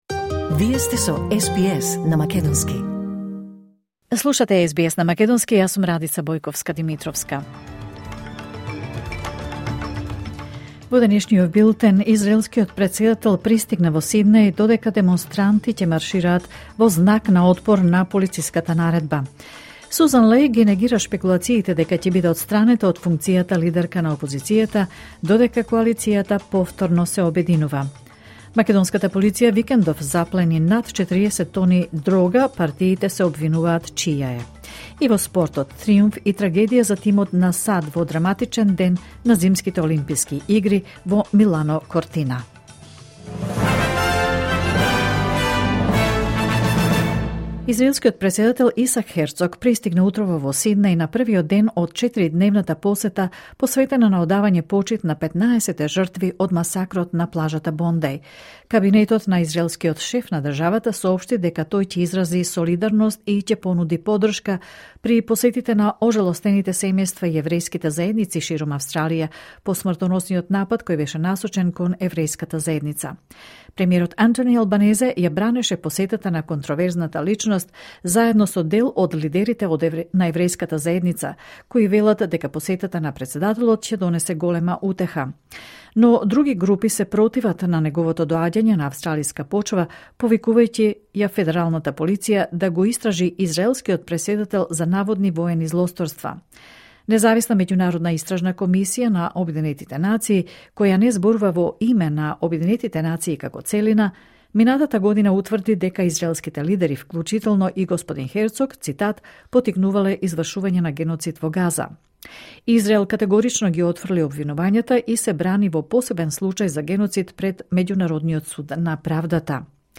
Вести на СБС на македонски 9 февруари 2026